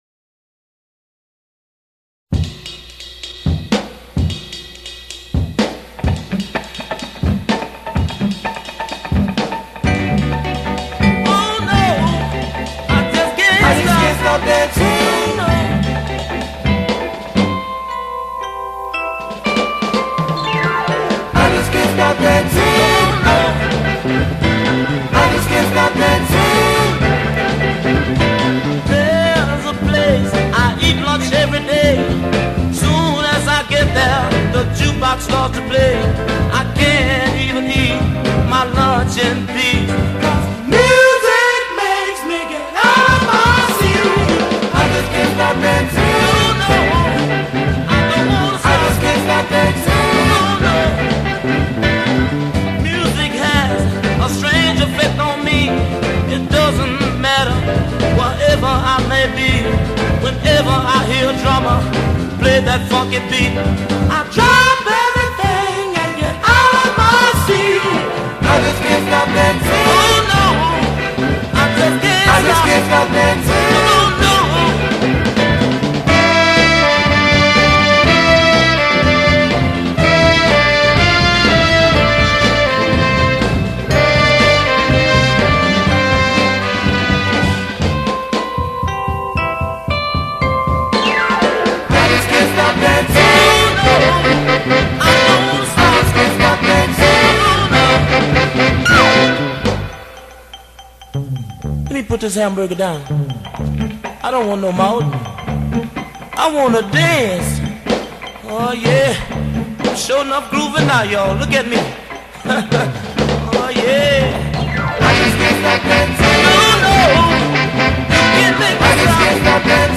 Soul / R&B group from Houston